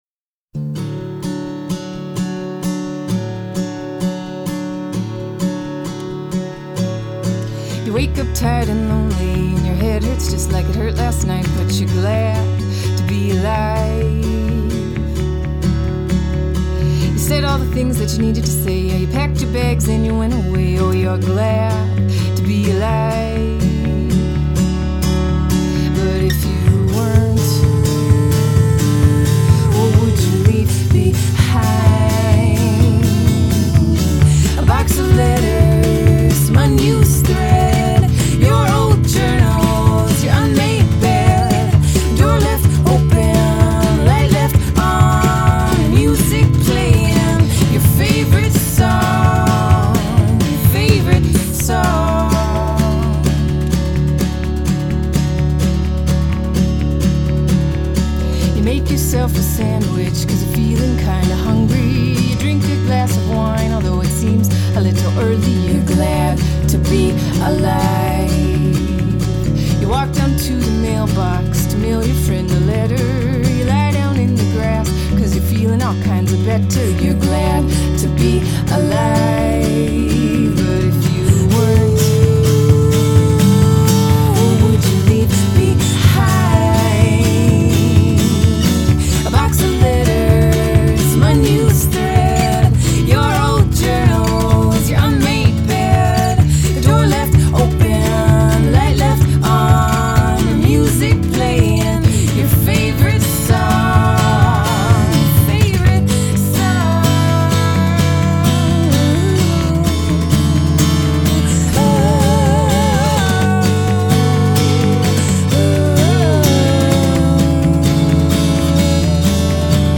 Singing sisters, w/ guitars, & unaffected vibe